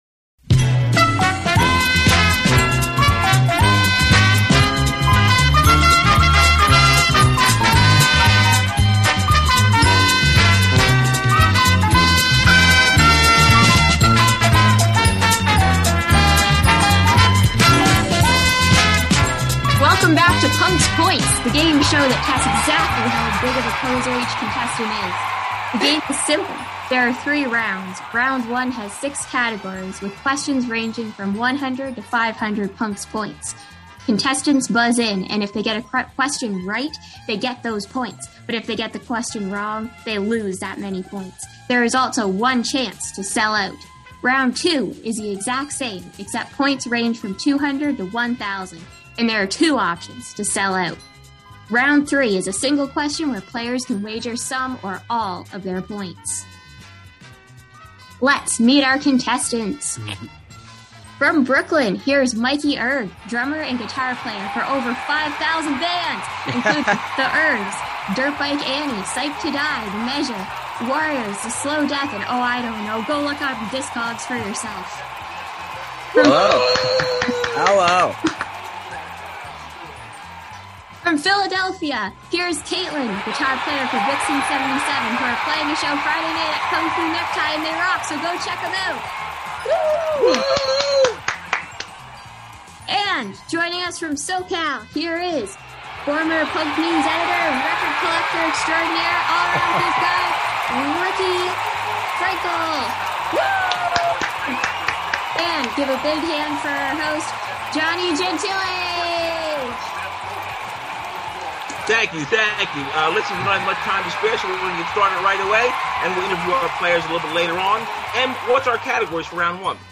America's favorite game show returns for another round of devastating punk trivia!